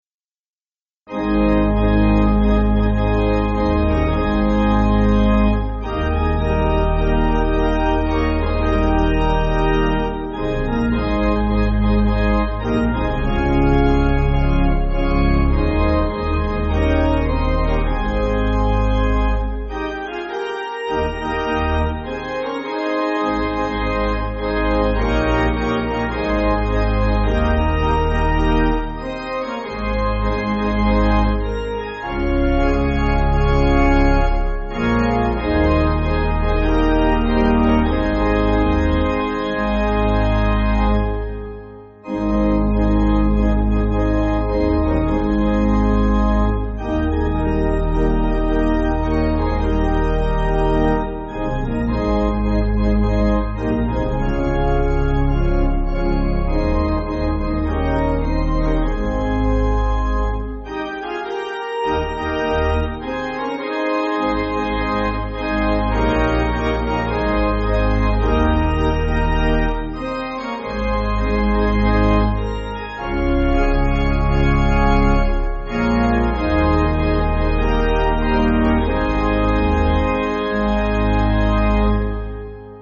(CM)   4/Ab